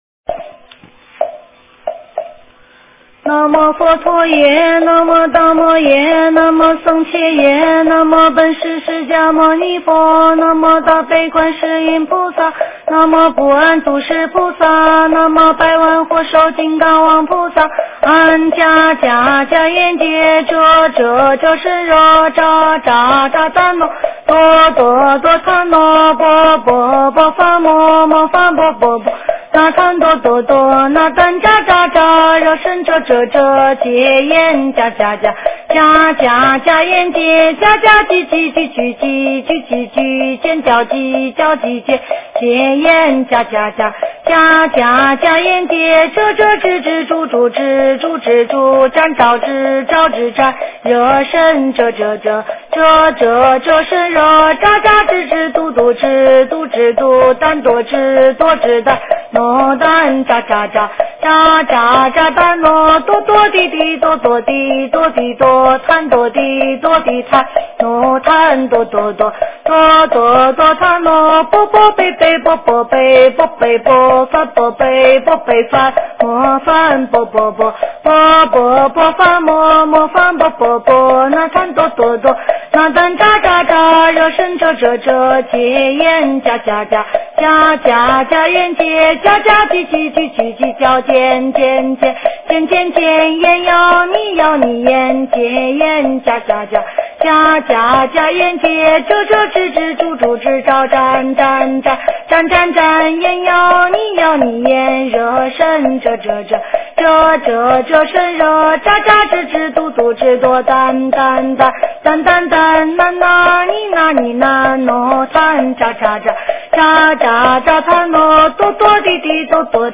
佛音 经忏 佛教音乐 返回列表 上一篇： 安土地真言--未知 下一篇： 般若波罗蜜多心经--新韵传音 相关文章 弥陀赞 炉香赞 上师三宝真言--僧团 弥陀赞 炉香赞 上师三宝真言--僧团...